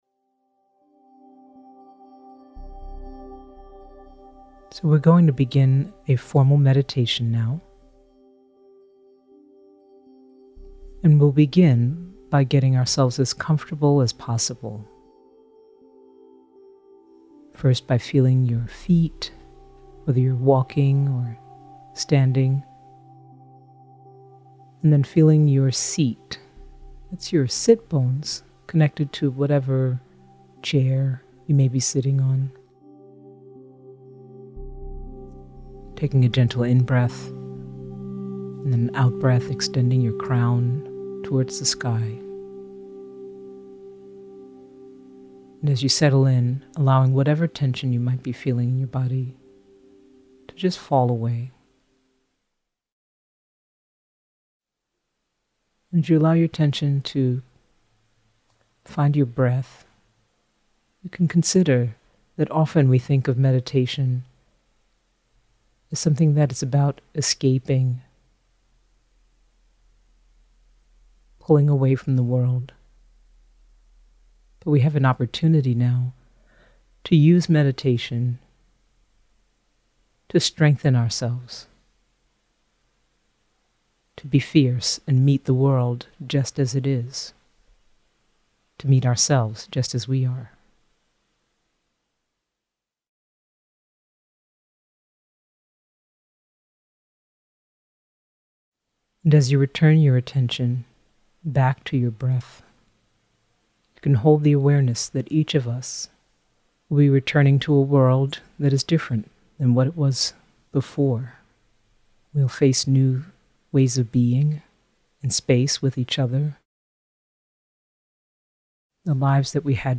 Day 3: Try a Fierce Meditation
fresh-start-challenge-day-3-fierce-meditation.mp3